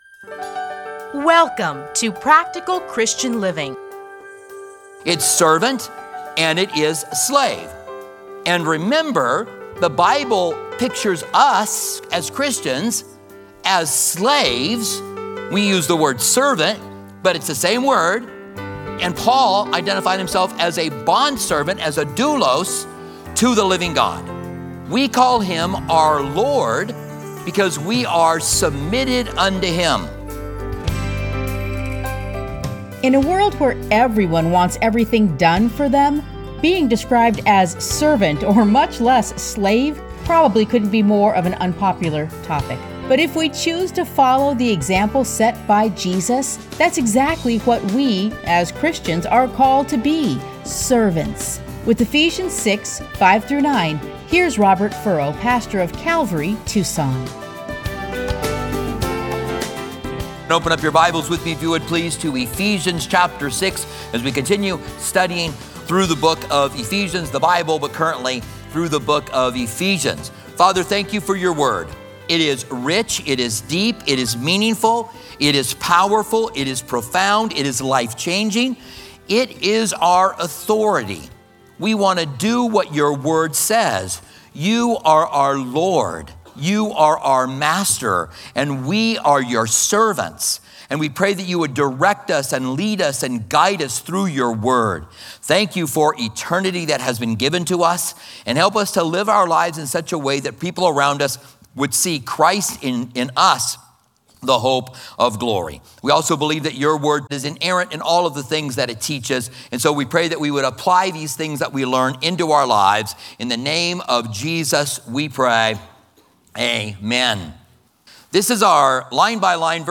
Listen to a teaching from Ephesians 6:5-9.